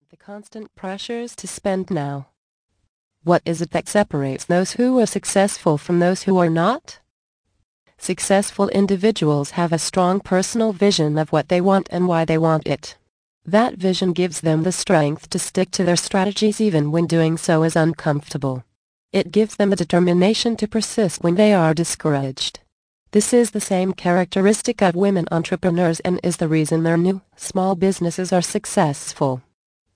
Easy Retirement Planning Tips Audio Book. Vol. 1 of 8